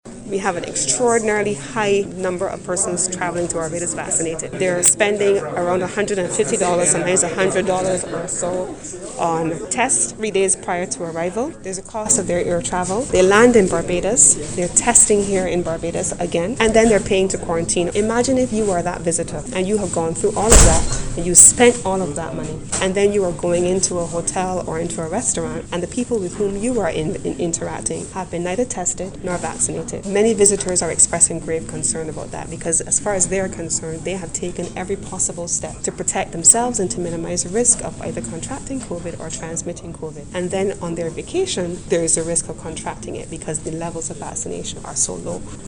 She was speaking to the media this morning about the Inaugural KLM flight to Barbados.